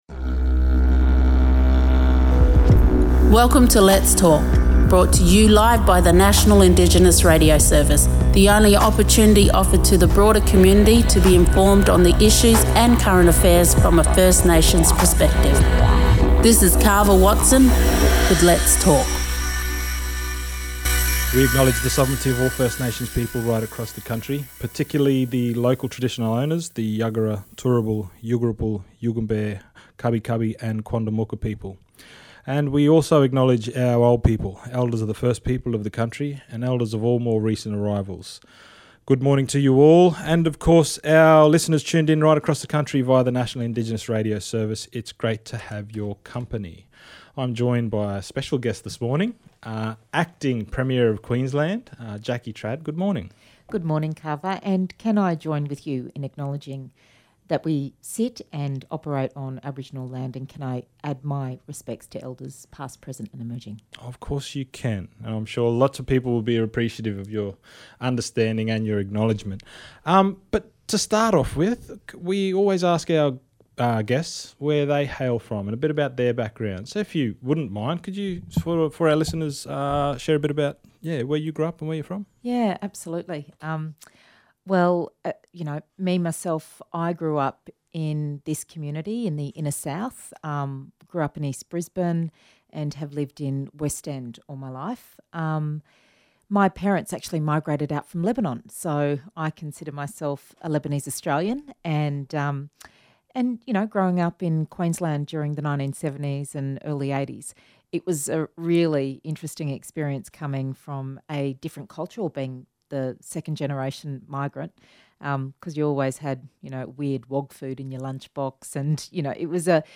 Queensland Deputy Premier, Treasurer & Minister for Aboriginal & Torres Strait Islander Partnerships Jackie Trad stopped by for a yarn